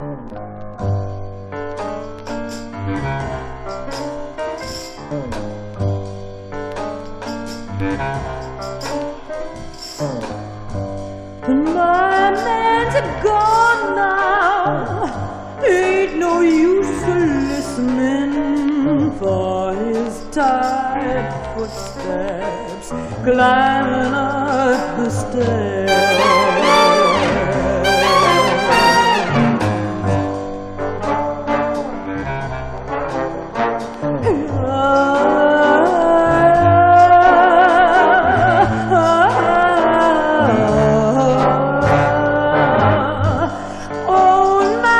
こちらは、ジャズ・ボーカル作品。
表現力が高いボーカルは、飽きのこない伸びやかさ。音もアレンジも妙技と思える最高さで彩り。
Jazz, Pop, Vocal　USA　12inchレコード　33rpm　Mono